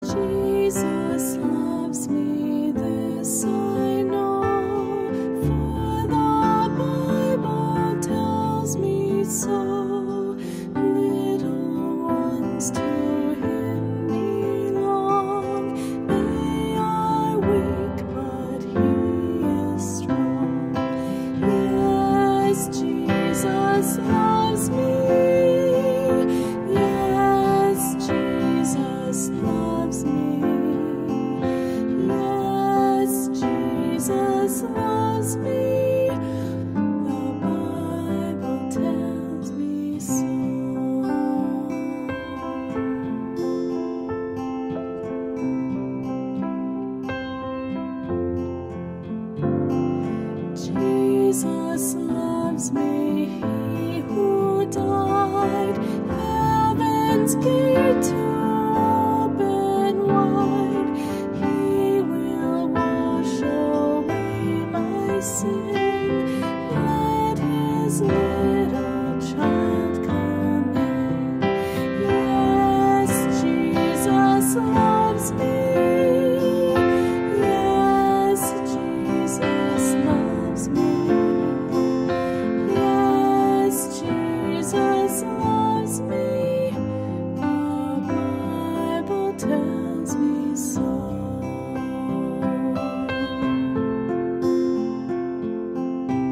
Sunday School Songs